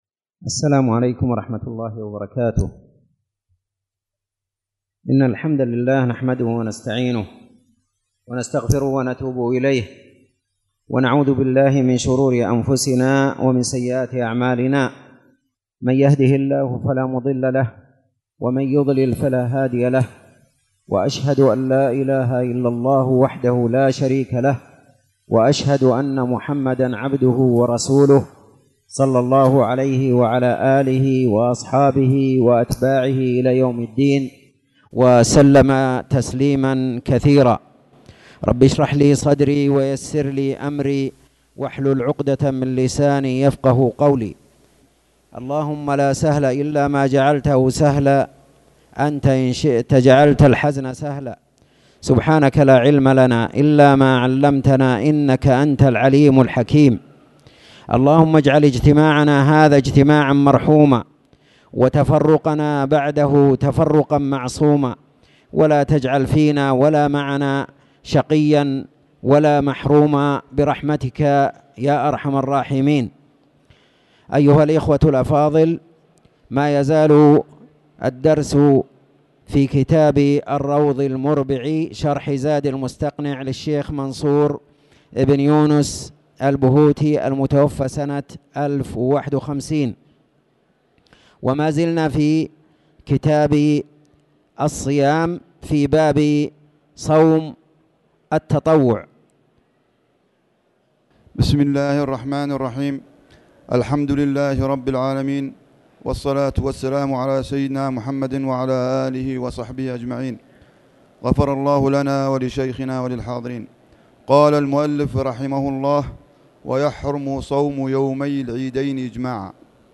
تاريخ النشر ٩ جمادى الأولى ١٤٣٨ هـ المكان: المسجد الحرام الشيخ